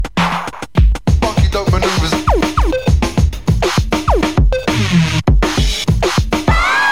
TOP >Vinyl >Grime/Dub-Step/HipHop/Juke
Side-B2 / Drum cuts